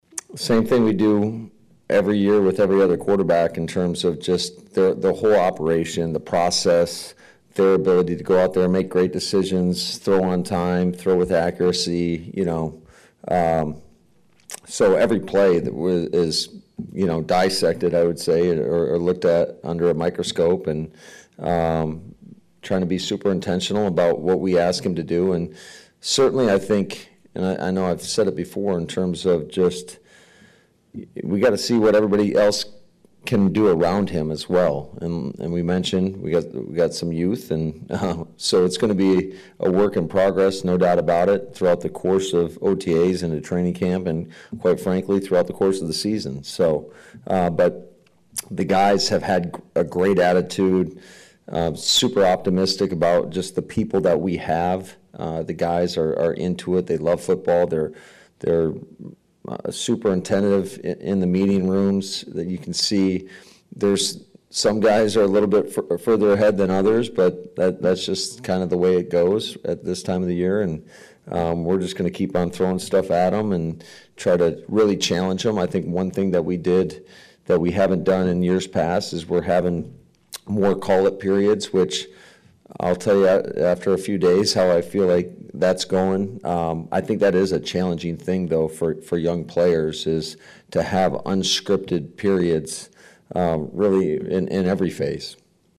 Following the 1:55 practice, LaFleur talked about what his expectations are for the team and his new starting quarterback.